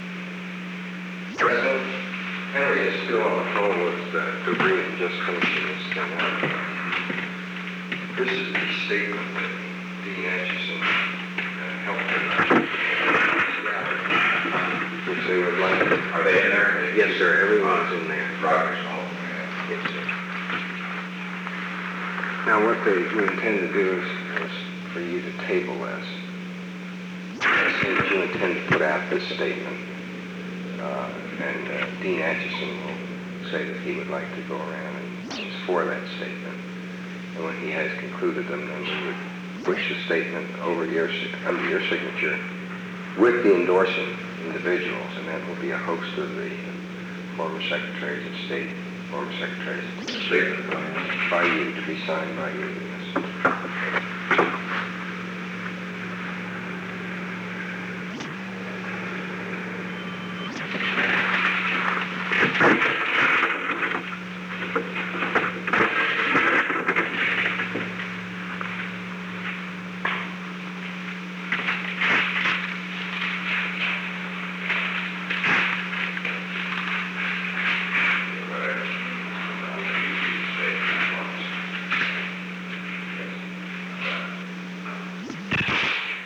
On May 13, 1971, President Richard M. Nixon and Alexander M. Haig, Jr. met in the Oval Office of the White House from 4:28 pm to 4:31 pm. The Oval Office taping system captured this recording, which is known as Conversation 498-016 of the White House Tapes.
Location: Oval Office